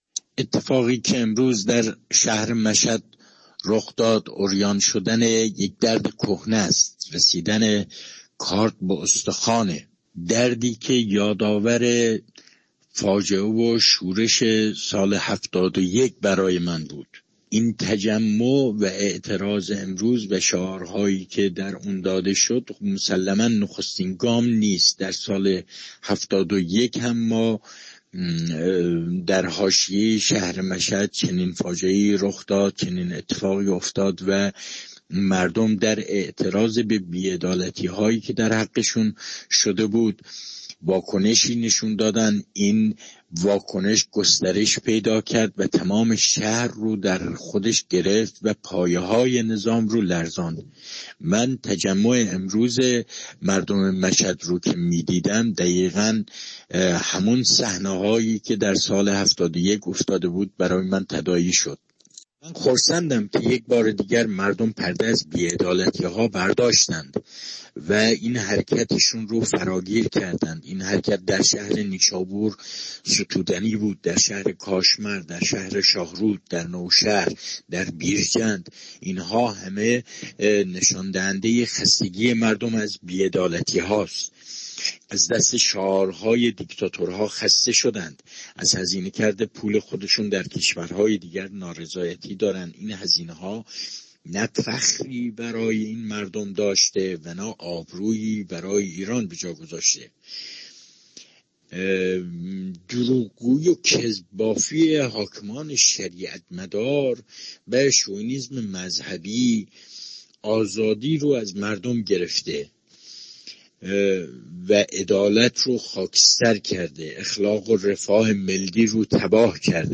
گزارش و تفسیر